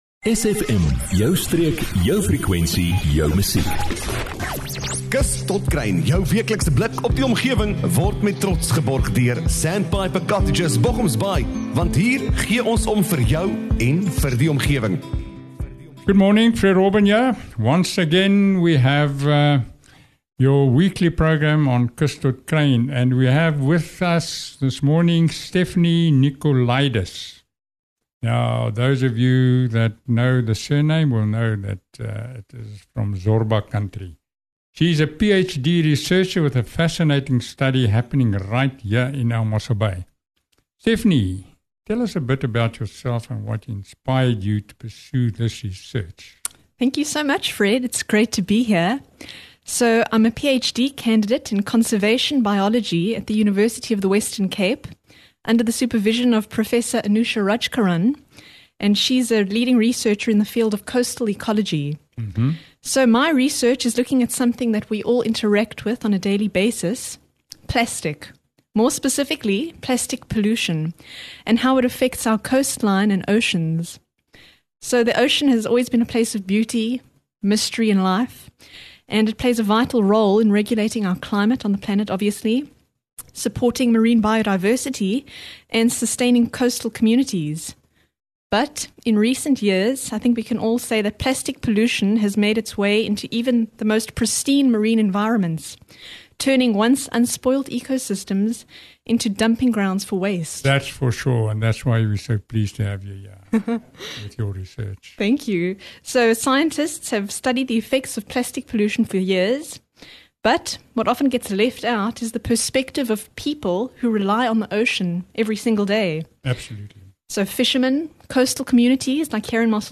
🌊 Interview Alert! 🌊